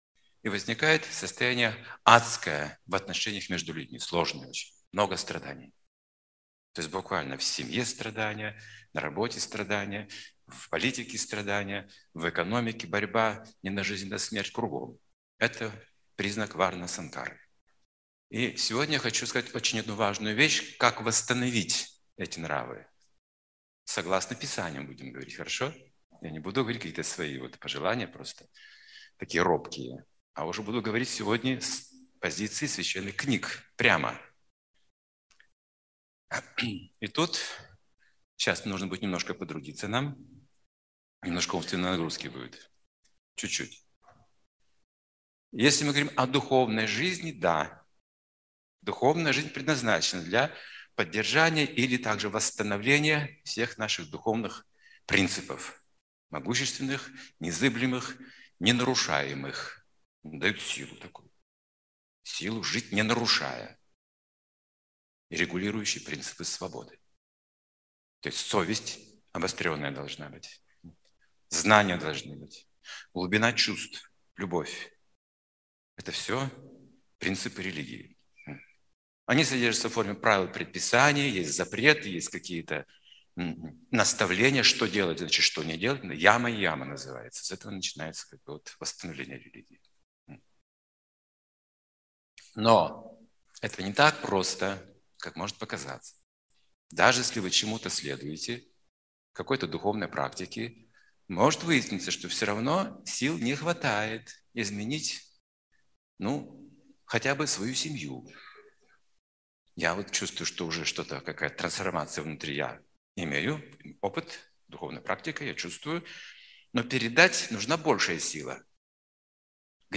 Лекции и книги